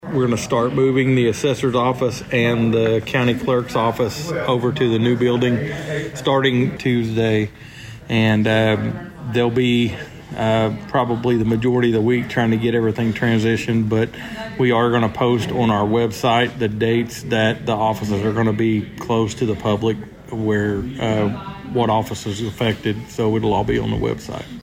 District Two Commissioner Steve Talburt says that starts this week.